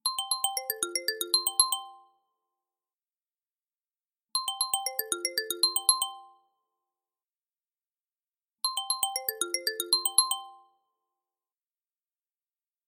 Звуки смартфона Самсунг
Оригинальный звук смс на samsung